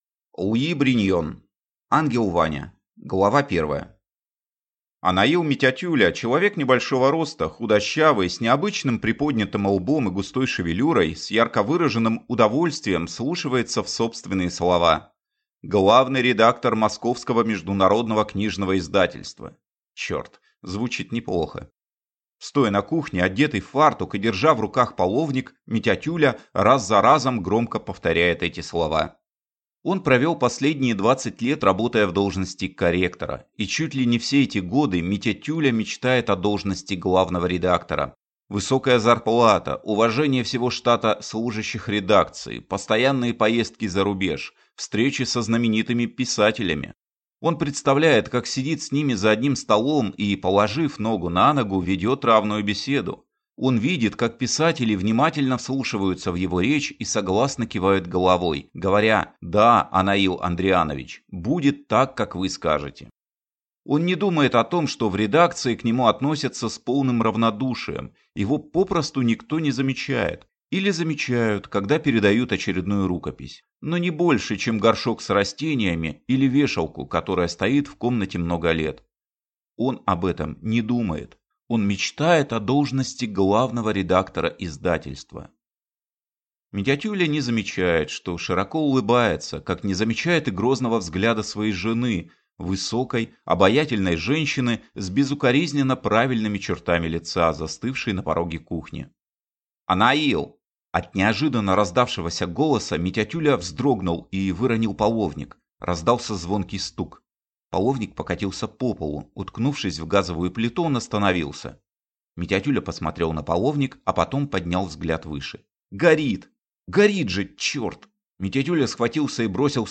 Аудиокнига Ангел Ваня | Библиотека аудиокниг